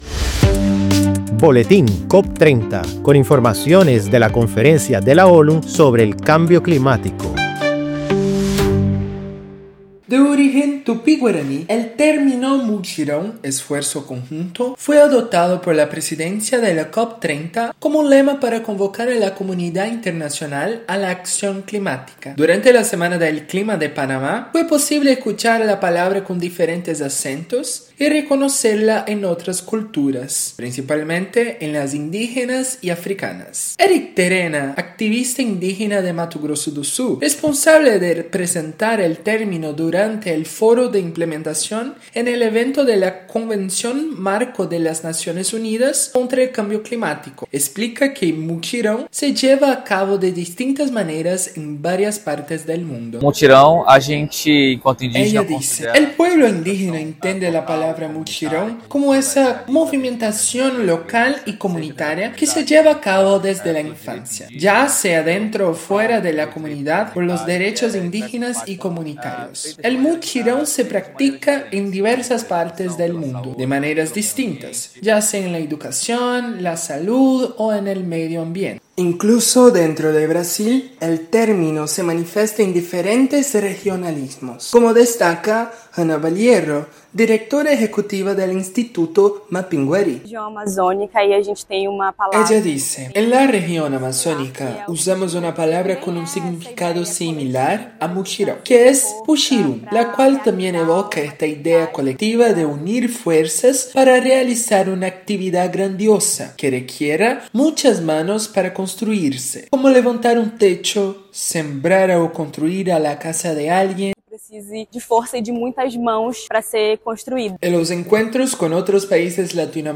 El término elegido por la presidencia de la Conferencia para convocar a la comunidad internacional a la acción climática se traduce en diversas lenguas indígenas y africanas. Escuche el reportaje para más información.